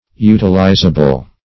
Utilizable \U"til*i`za*ble\, a.